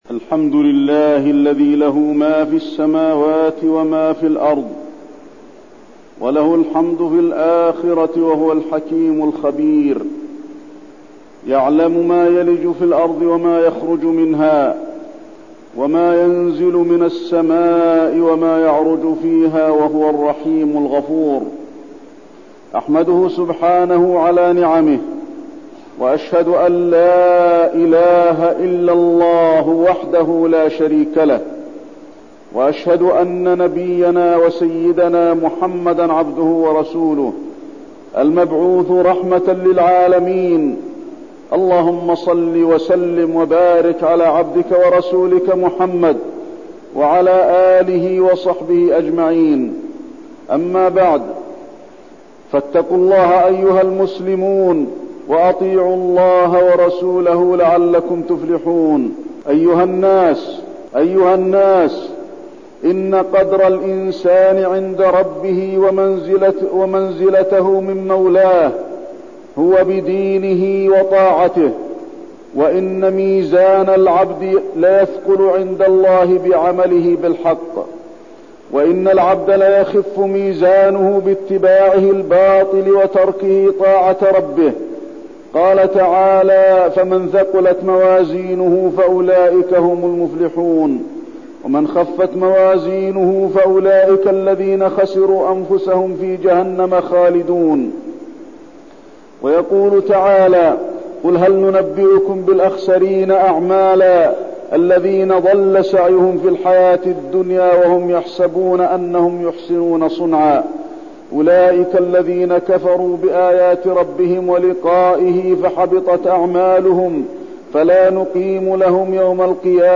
تاريخ النشر ١٢ ربيع الثاني ١٤١٠ هـ المكان: المسجد النبوي الشيخ: فضيلة الشيخ د. علي بن عبدالرحمن الحذيفي فضيلة الشيخ د. علي بن عبدالرحمن الحذيفي مكانة الإنسان عند ربه